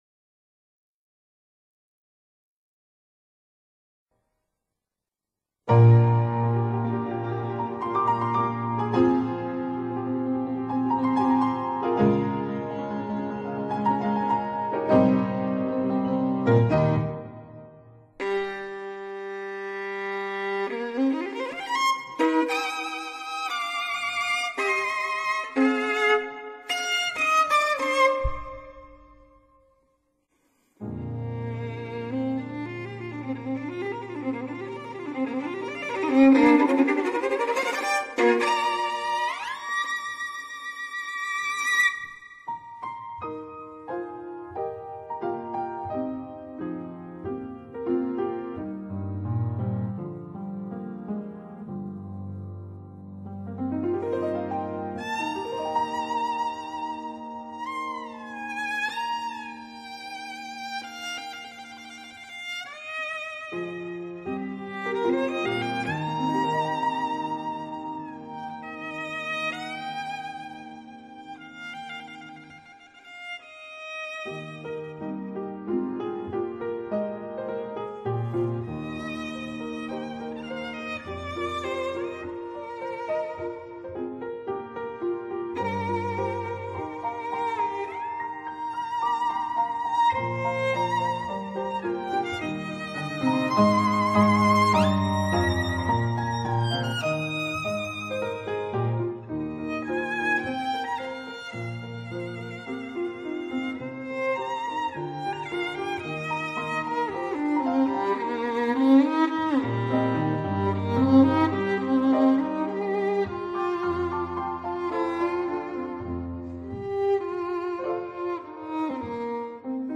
该曲目改编十分成功,充分发挥小提琴歌唱性的优点,加上民族化的滑音技巧,优美抒情。